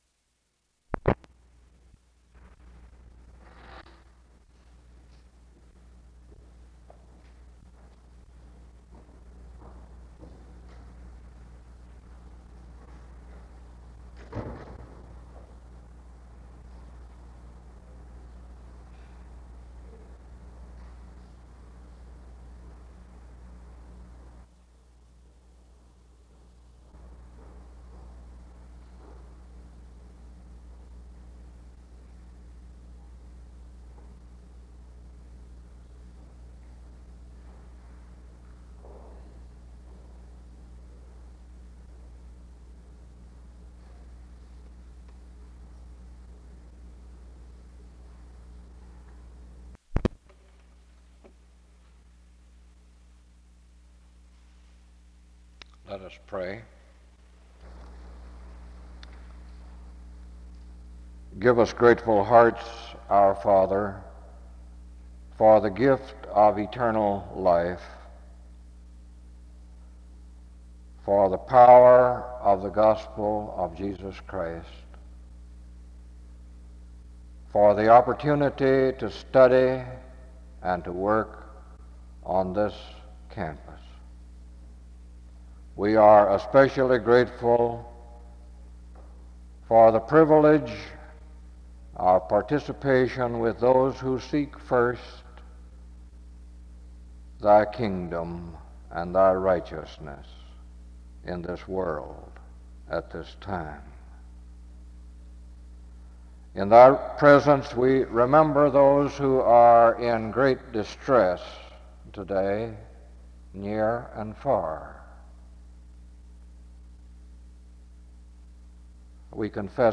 Download .mp3 Description There is silence (00:00-00:54) before the service opens in prayer (00:55-02:50).
Another prayer is offered in the audio (29:26-30:20).